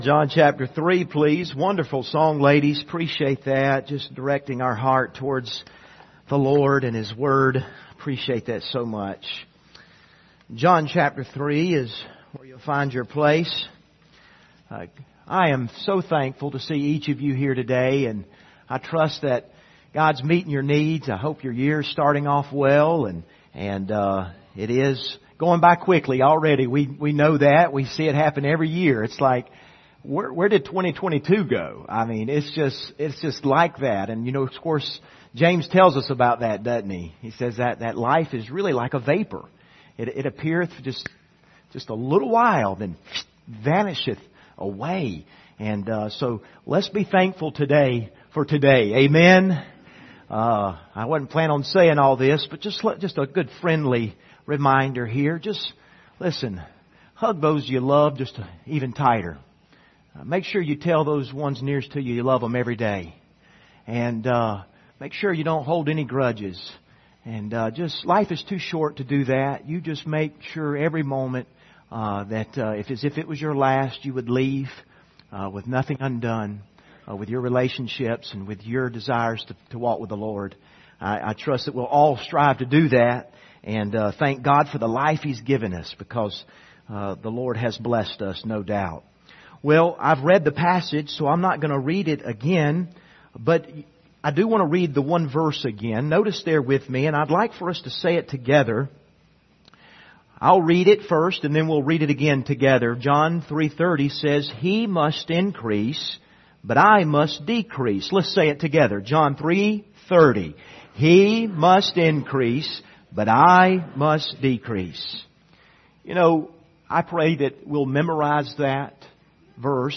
Passage: John 3:22-36 Service Type: Sunday Morning View the video on Facebook « Responding to Unbelievers What is Revival?